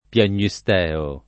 piagnisteo [ pian’n’i S t $ o ] s. m.